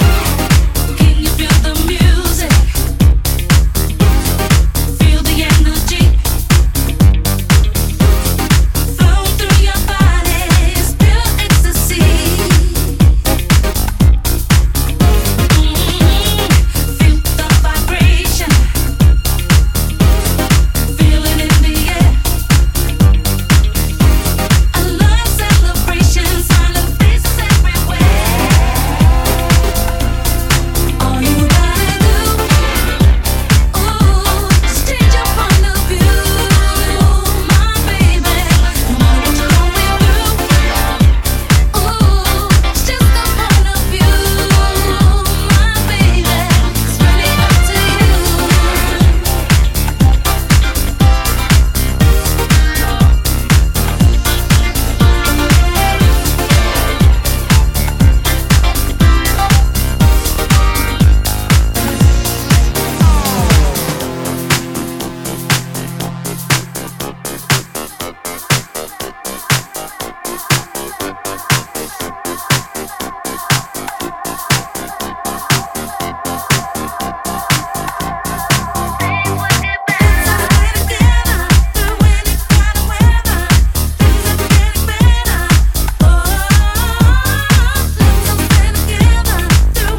ディスコにインスパイアされた爽快ブギー・ディスコ・ハウス！
ジャンル(スタイル) DISCO / HOUSE